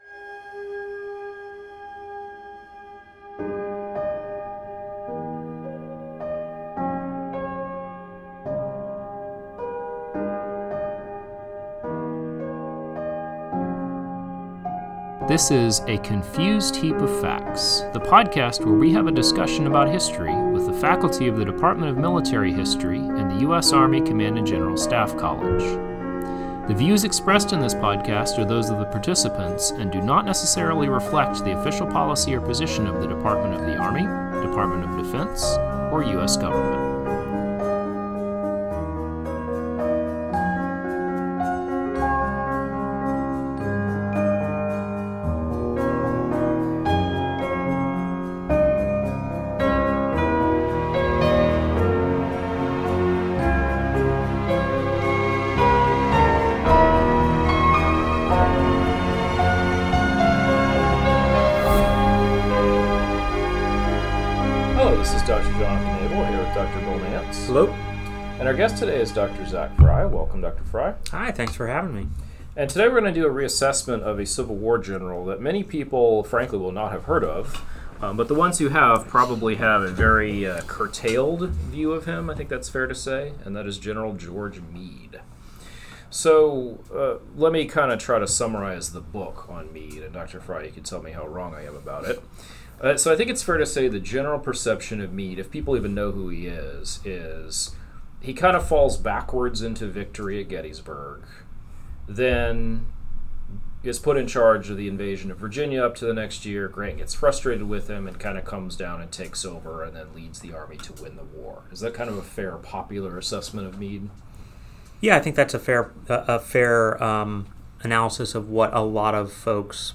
Historians from the CGSC Department of Military History discuss topics in military history, ranging from antiquity to the near-present.